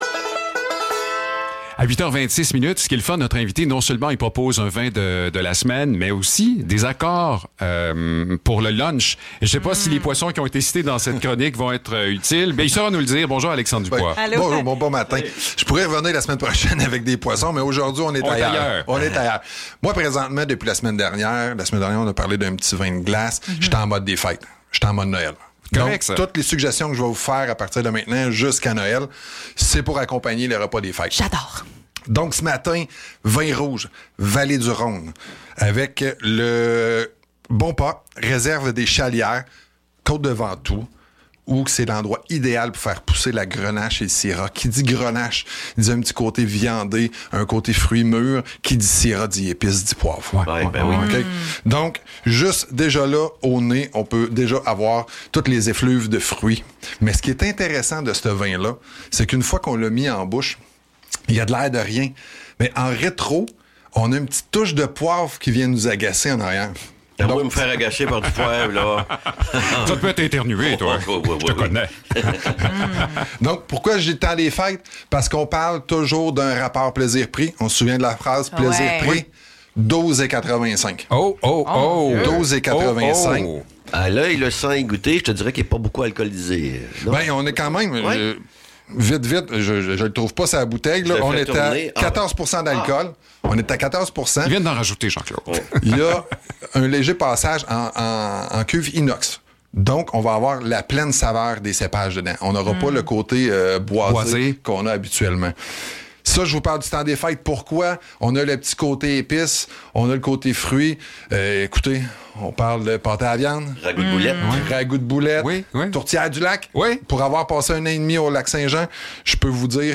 Chronique Vins